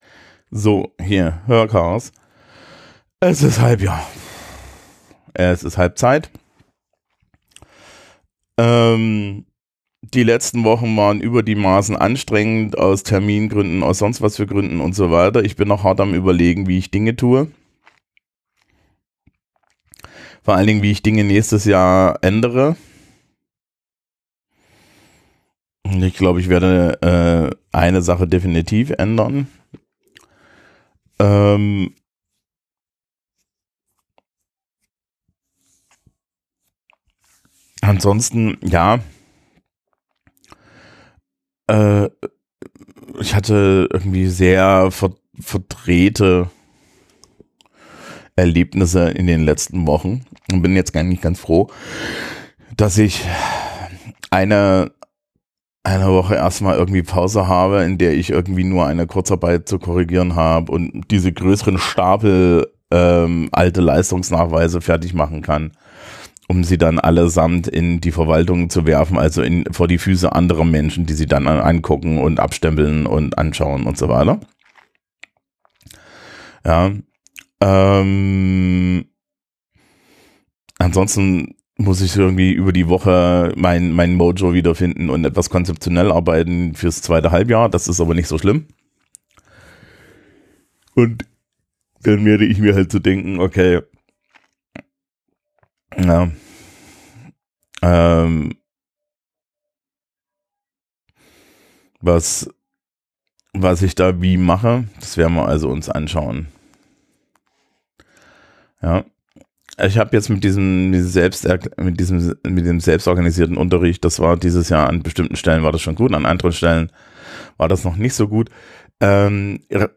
Kurz und rambly zum Halbjahr.